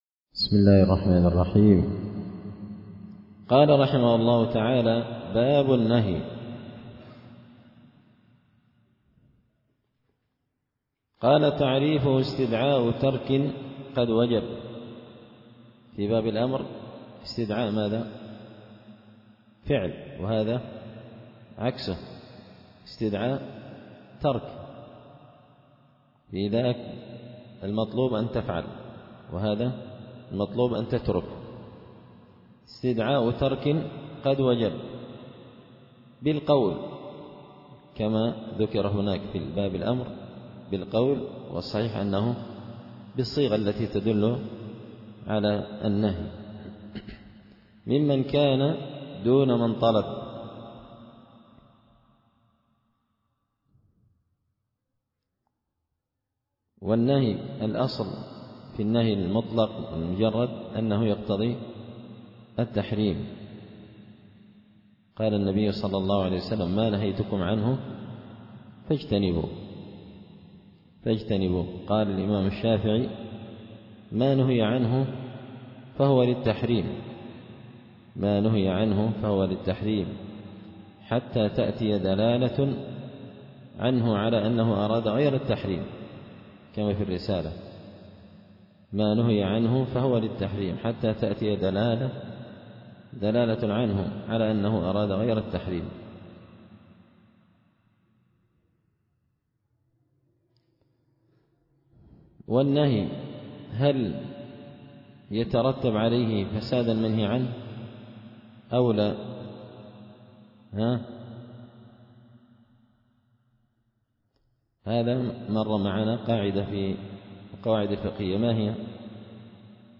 دار الحديث بمسجد الفرقان ـ قشن ـ المهرة ـ اليمن